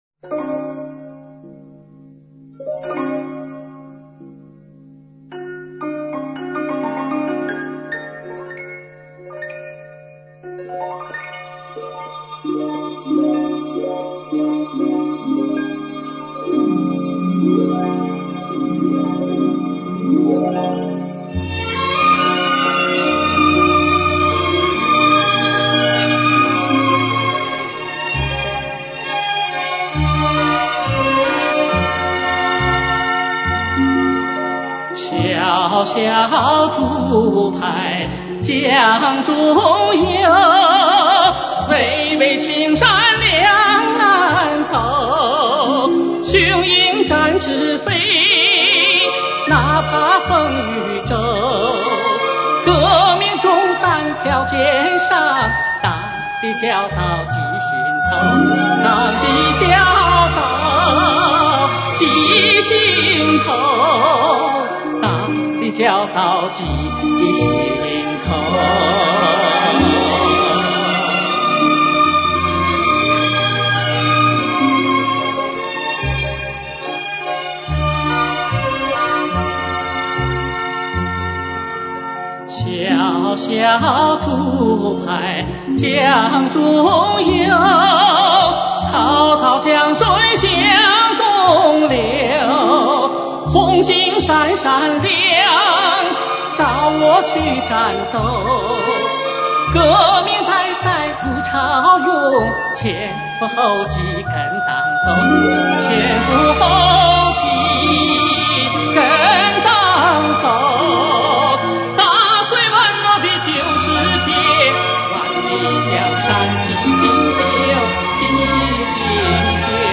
影视 戏曲歌曲专辑
基本选取的是历史录音
演唱热情奔放，富有乐感，吐字清晰，声音流畅，高音稳定、透明，辉煌而华丽。
电影原版